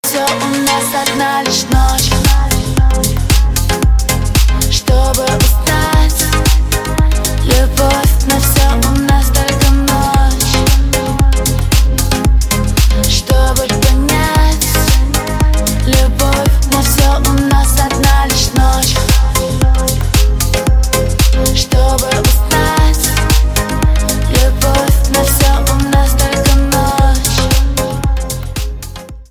• Качество: 320, Stereo
поп
женский вокал
чувственные